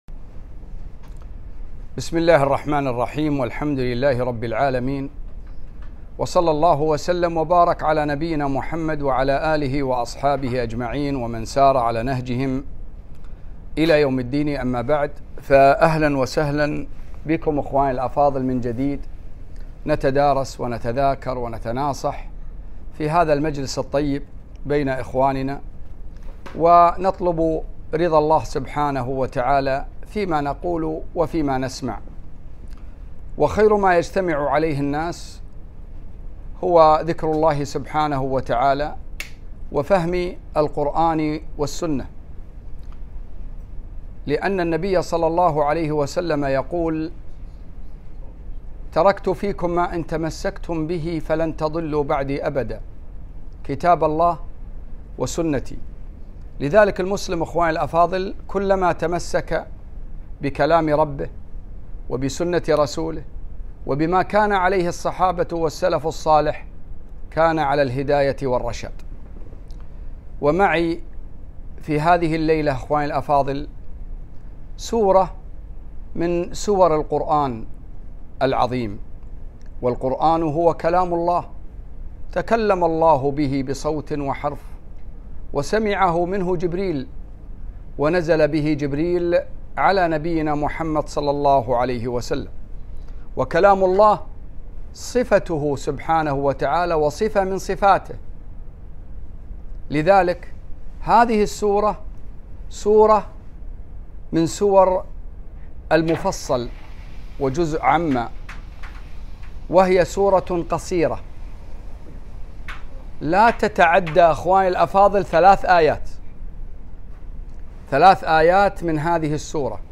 محاضرة - تفسير سورة العصر